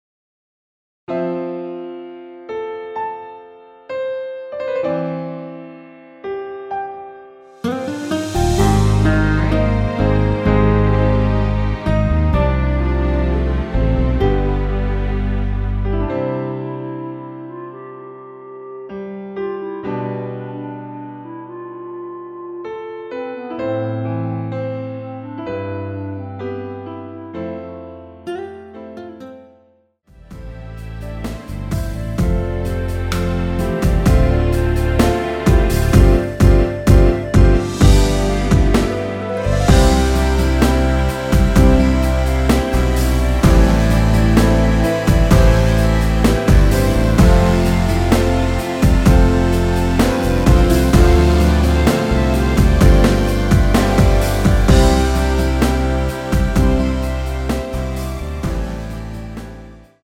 원키에서(-2)내린 멜로디 포함된 MR입니다.
◈ 곡명 옆 (-1)은 반음 내림, (+1)은 반음 올림 입니다.
앞부분30초, 뒷부분30초씩 편집해서 올려 드리고 있습니다.
중간에 음이 끈어지고 다시 나오는 이유는